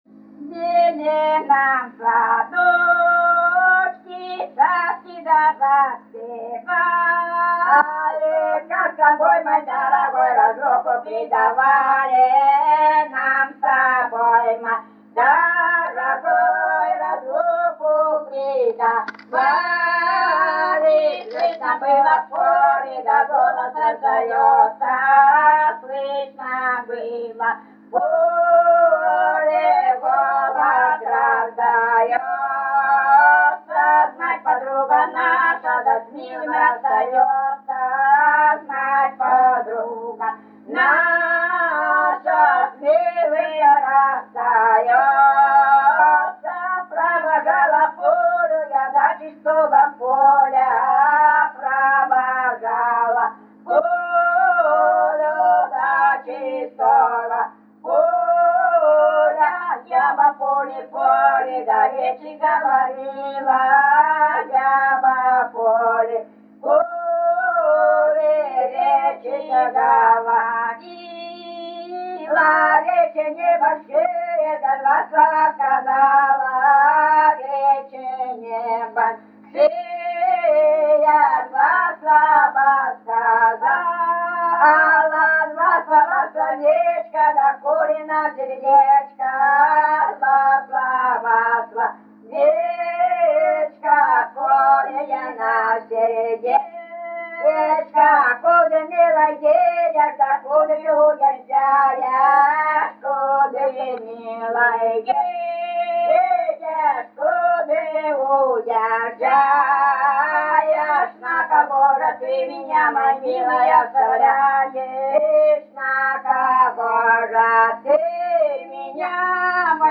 Ланцовые песни
Место записи: с. Шуньга, Медвежьегорский район, Республика Карелия.